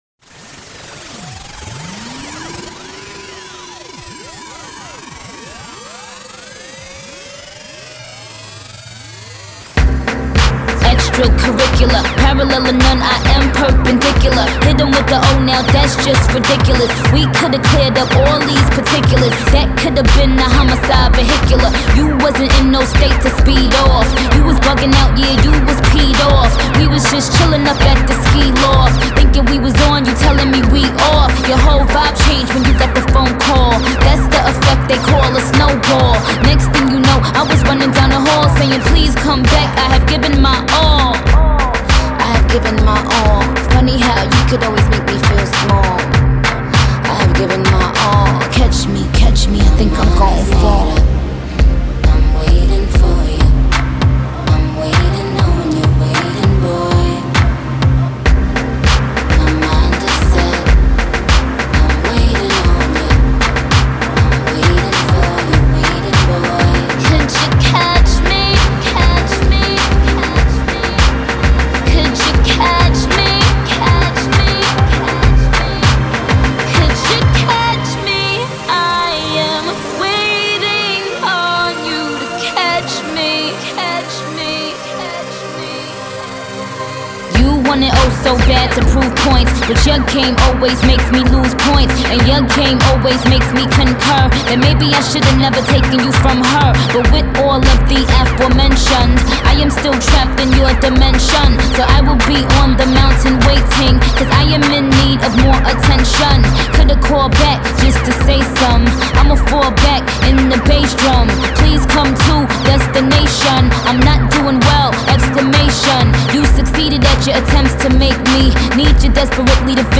While still synth heavy
The arrangements here are darker